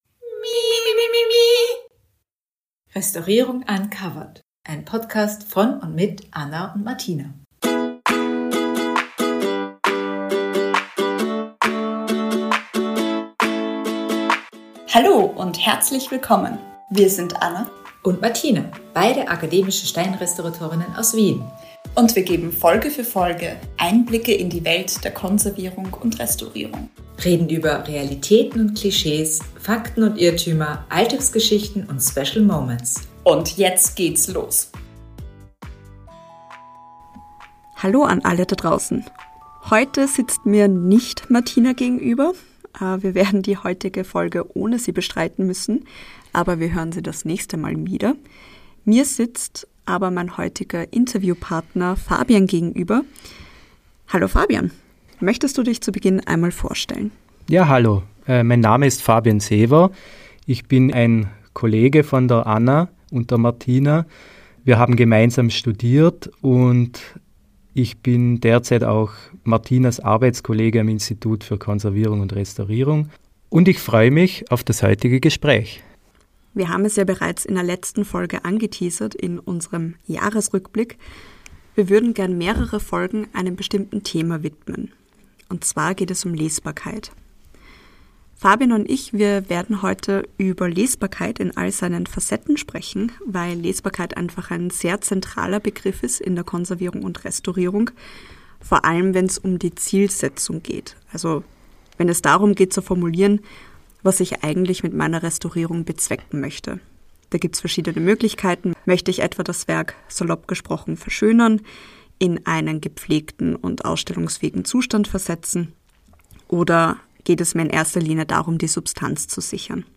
Restaurator und Kunsthistoriker